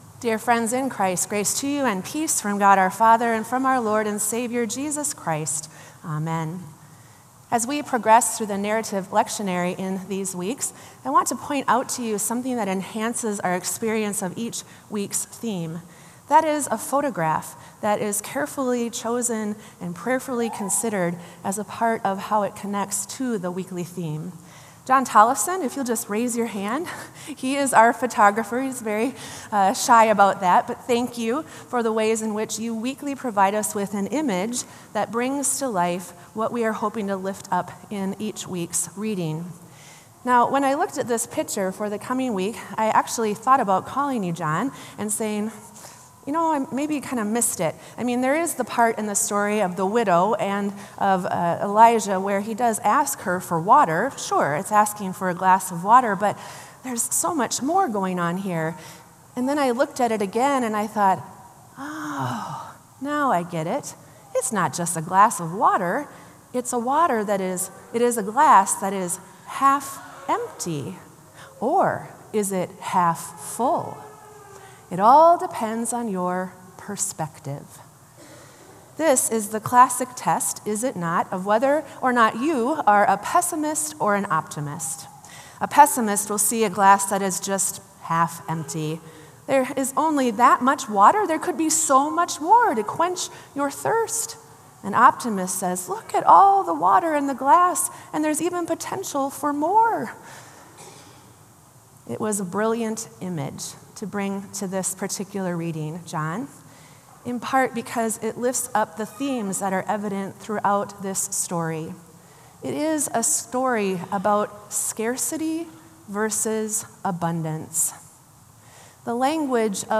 Sermon “The Lord Provides”